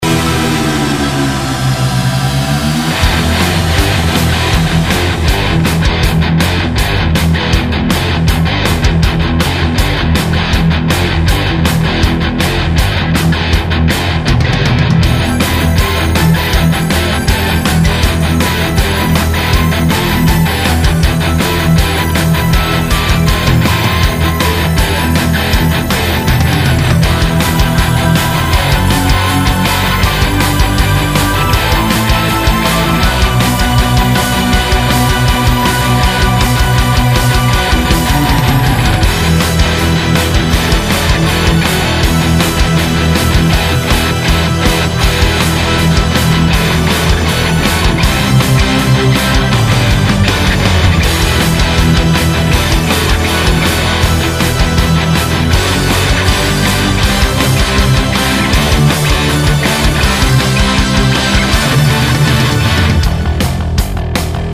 В итоге получается "пяное" звучание с явными признаками Стреча ВО ВСЕХ:wacko: фрагментах.. даже тех, которые не ровнял...